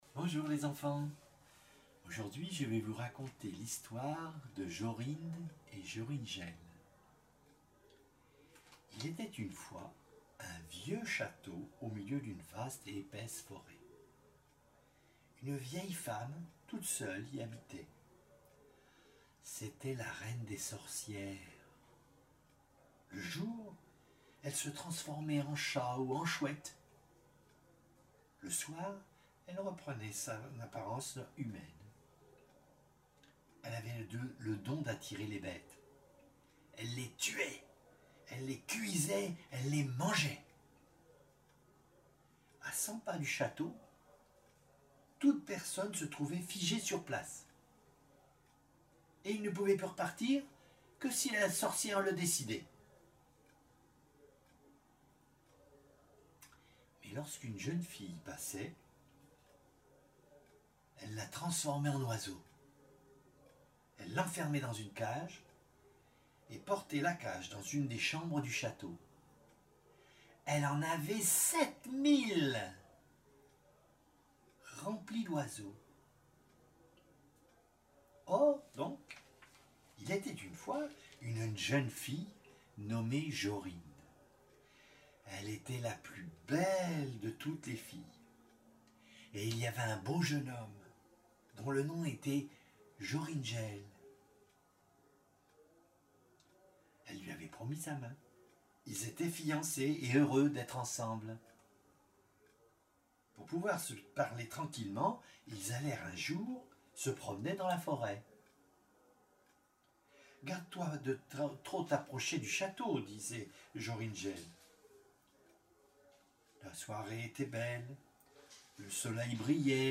Un Conte de Grimm